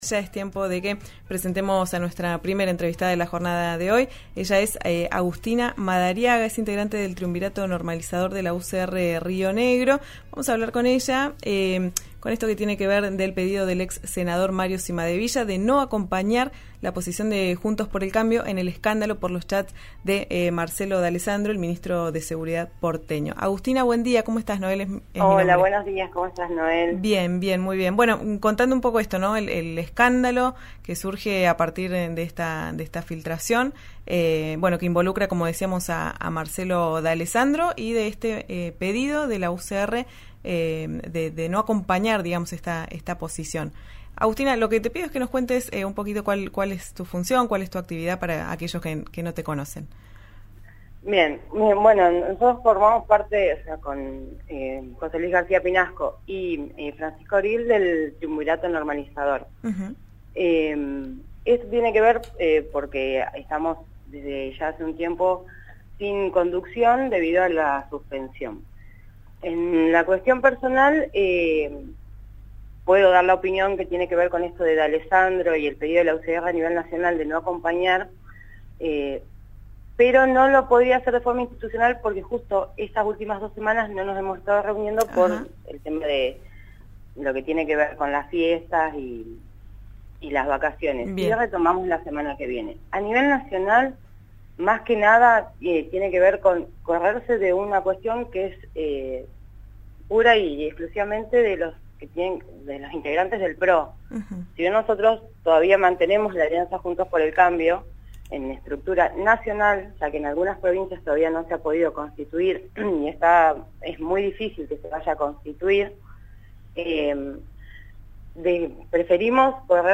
Si bien dese el principio de la entrevista aclaró que su opinión es personal y no institucional, aseguró que “hay cuestiones como partido que no podemos dejar pasar, más allá que el PRO sean nuestros socios electorales y aliancistas”.